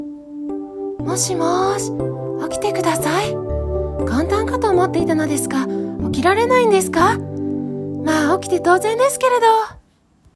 胡蝶しのぶの声まねで目覚ましボイス